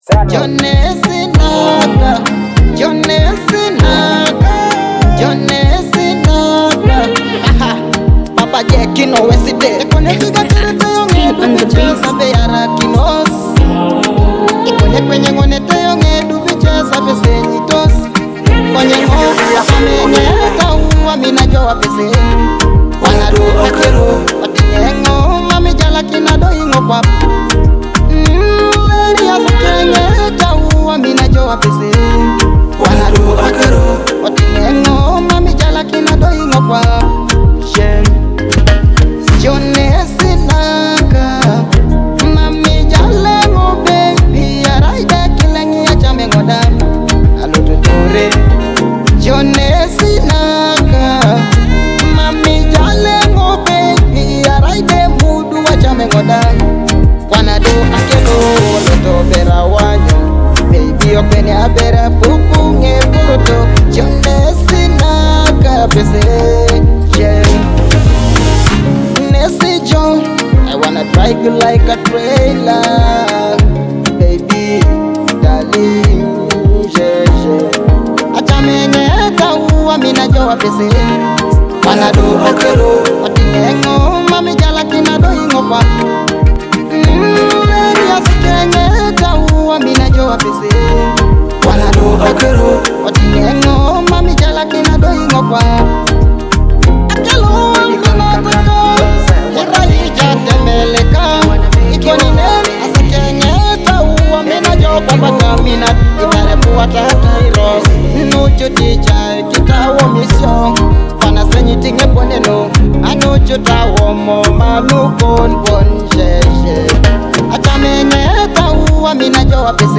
energetic beats, soulful vocals, and powerful storytelling.